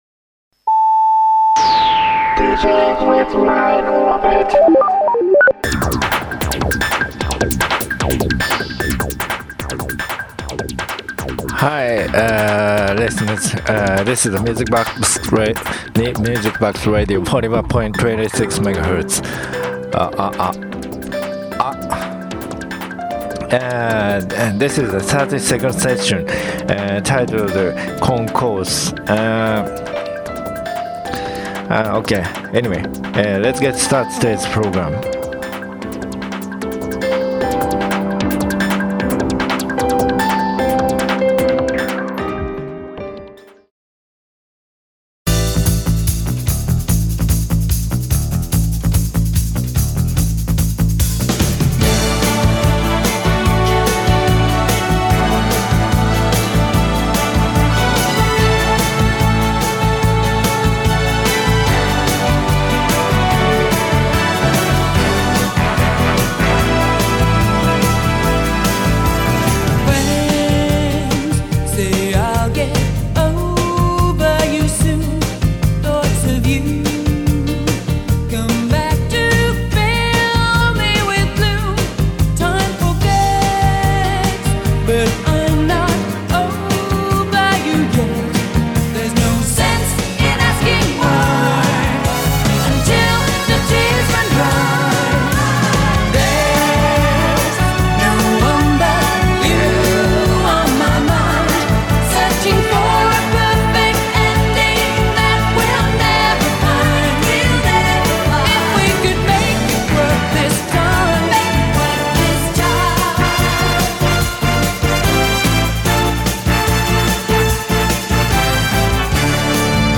In an Airport or a Park in Autumn. 90s Hits